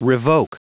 Prononciation du mot revoke en anglais (fichier audio)
revoke.wav